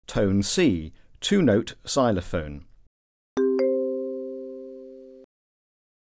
Alert Tone: C